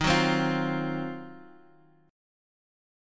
D#m6add9 Chord
Listen to D#m6add9 strummed